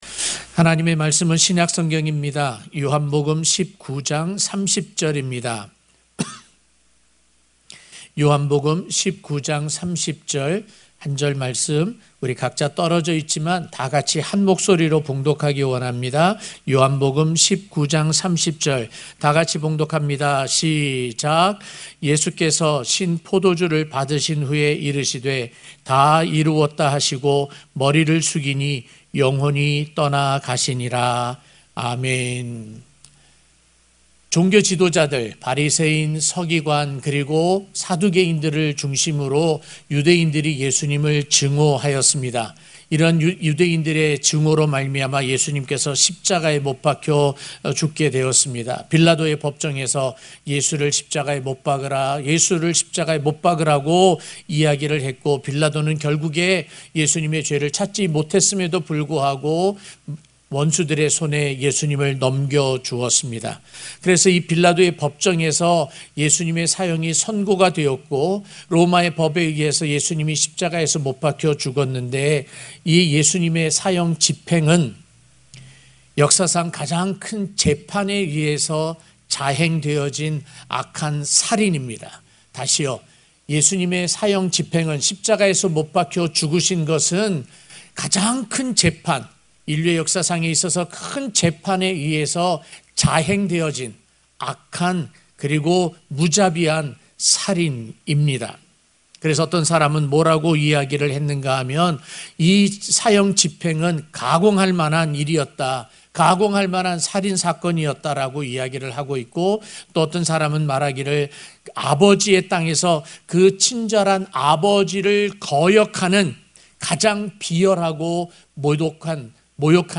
성금요일설교-나의 사랑 십자가 (요 19:30)